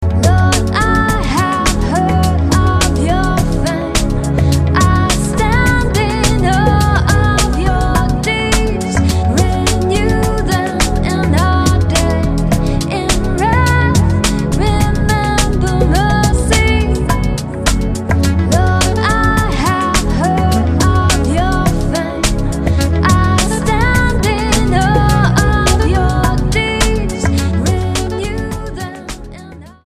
STYLE: Ambient/Meditational
keys, sax, trumpet, guitars and drum loops